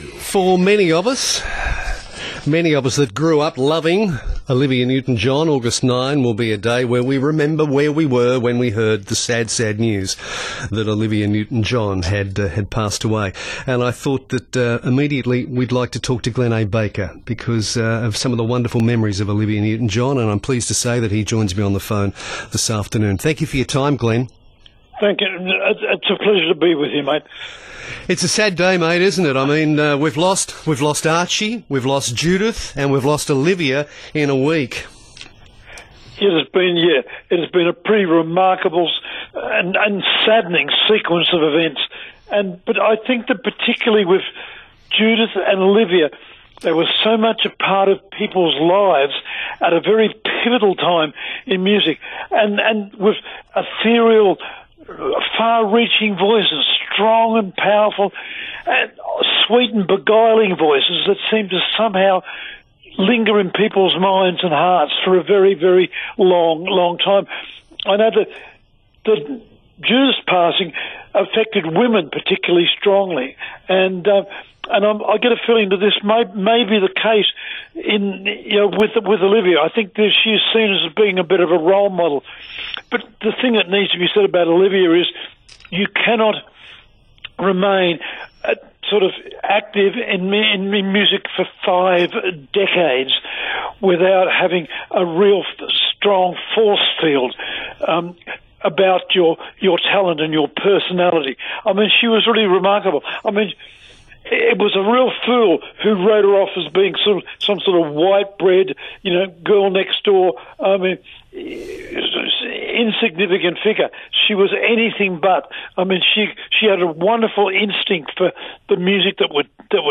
joined by Journalist and Rock Music Expert Glenn A. Baker